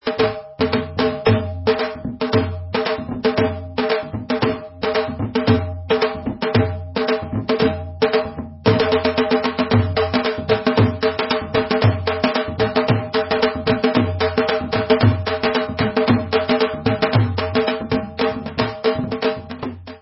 Dhol Patterns 2